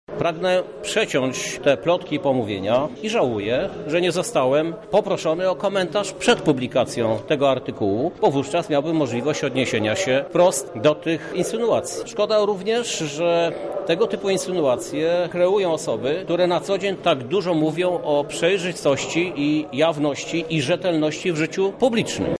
Swoje stanowisko przedstawia prezydent Lublina Krzysztof Żuk: